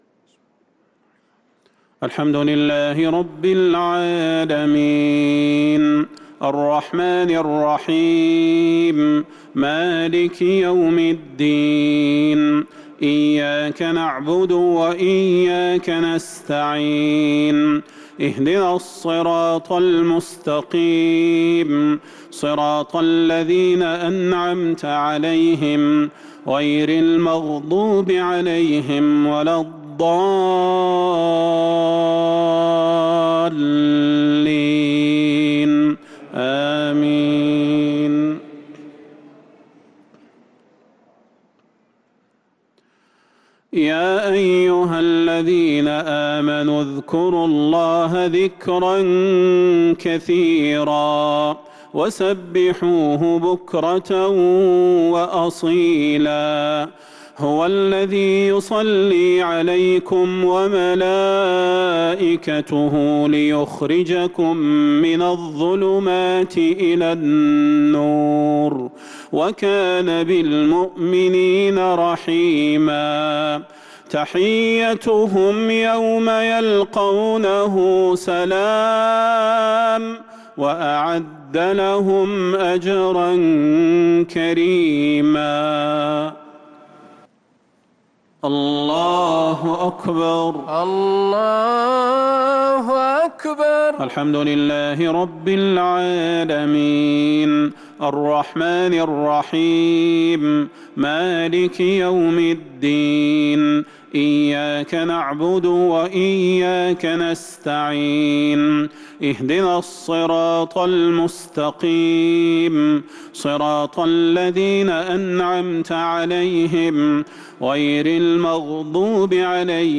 صلاة العشاء للقارئ صلاح البدير 15 رمضان 1443 هـ
تِلَاوَات الْحَرَمَيْن .